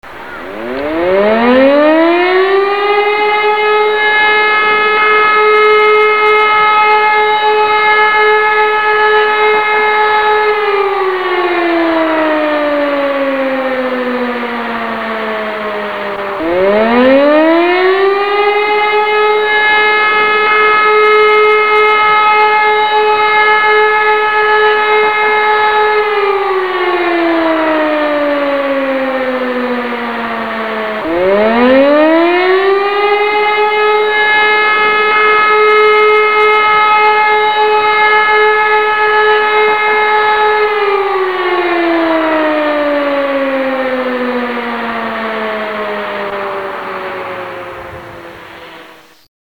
FEUERWEHRSIRENE
Ist ein Heulton der 3x 15 Sekunden mit 7 Sekunden Pause dazwischen dauert.
sirenen_einsatz.mp3